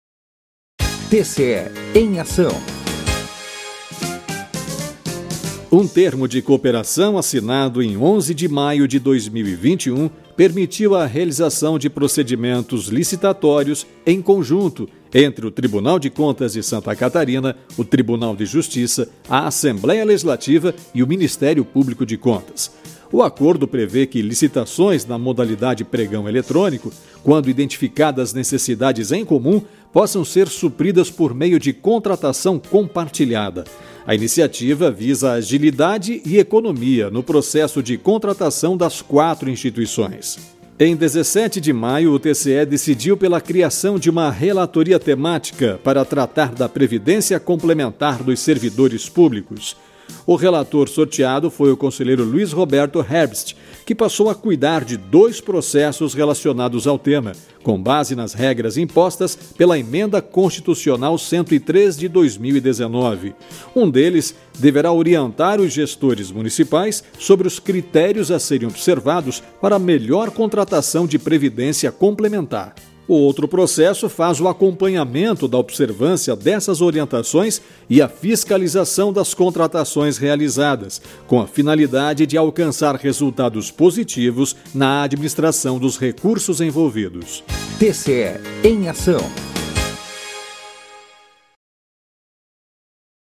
VINHETA – TCE EM AÇÃO